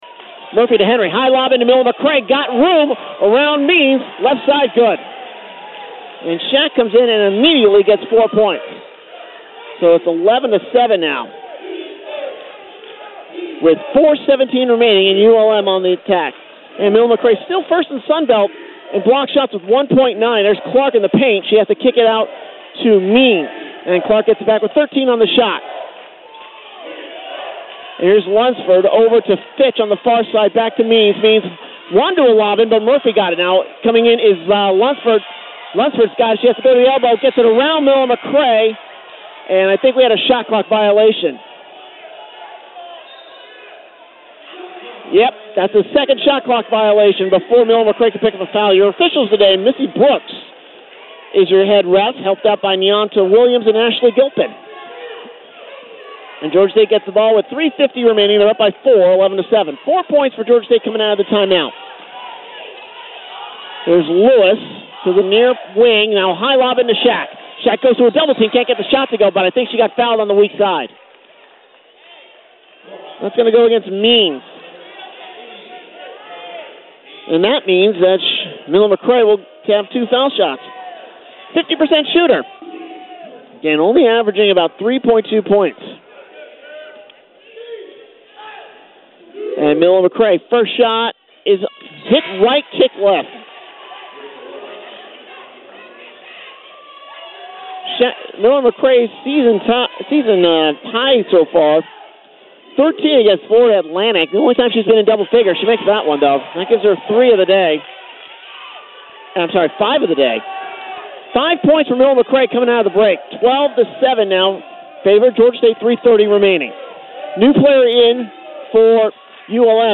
A collection of my play-by-play clips.